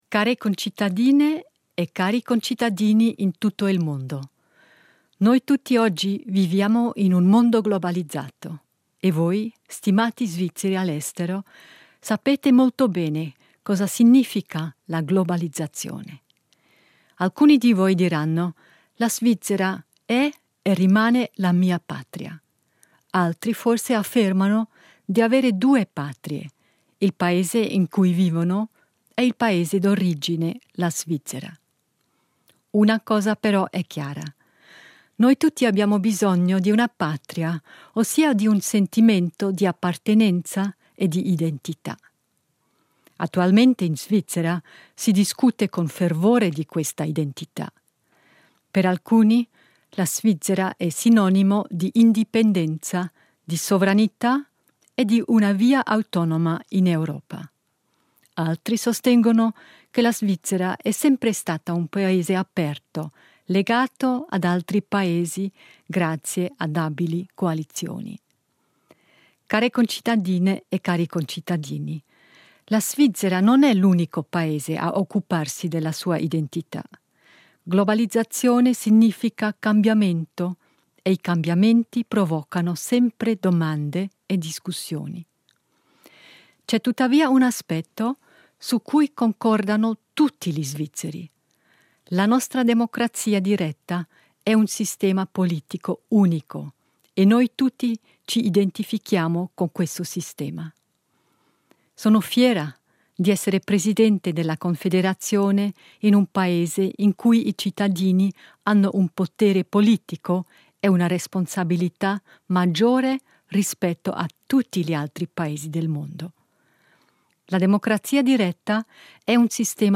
Come da tradizione, la presidente della Confederazione Simonetta Sommaruga rivolge un messaggio agli Svizzeri all'estero.
In occasione della Festa nazionale svizzera del 1º agosto la presidente della Confederazione Simonetta Sommaruga si rivolge ai circa 750'000 svizzeri che vivono all’estero.